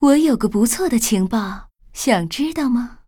文件 文件历史 文件用途 全域文件用途 Dana_tk_03.ogg （Ogg Vorbis声音文件，长度3.1秒，100 kbps，文件大小：37 KB） 源地址:游戏语音 文件历史 点击某个日期/时间查看对应时刻的文件。